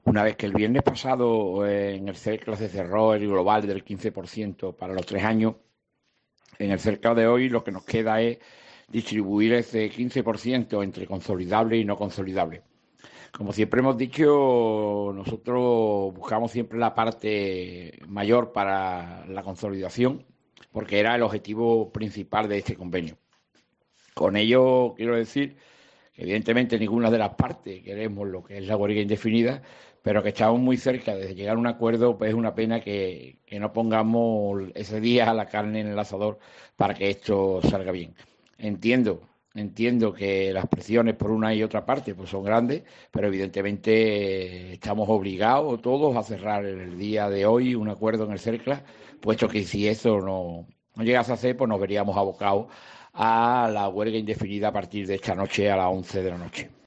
Tanto su tono como su mensaje inducen a la esperanza en un acuerdo que deje de lado definitivamente la idea de la huelga general que, de no alcanzarse en la reunión del SERCLA de este lunes, se pondría en marcha a las 23:00 horas de hoy mismo.